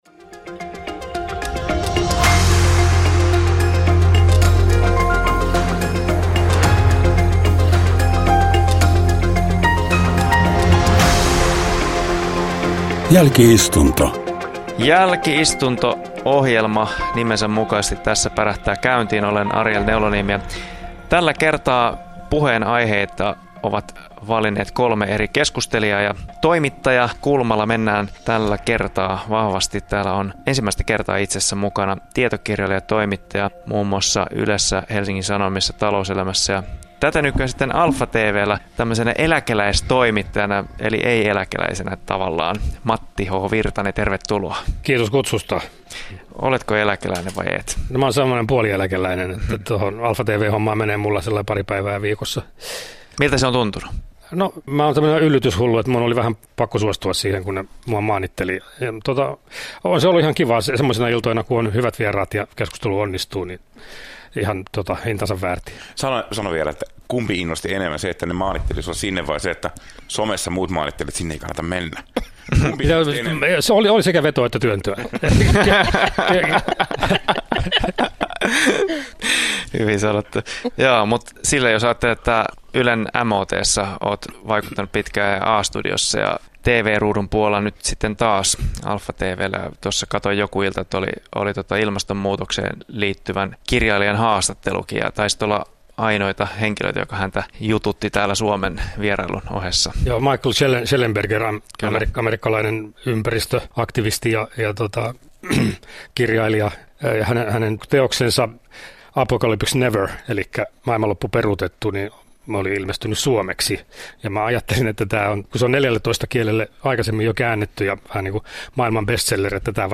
Jälki-istunto kokoaa tälläkin viikolla keskustelijat eri näkökulmien takaa.